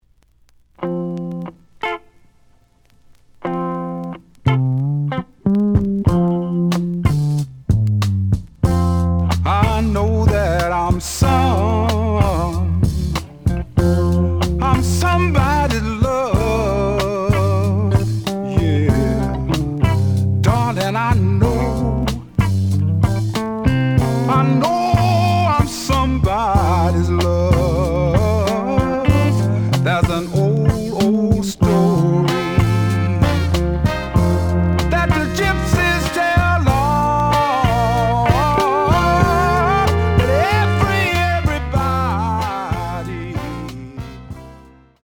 The audio sample is recorded from the actual item.
●Format: 7 inch
●Genre: Soul, 60's Soul
Slight edge warp.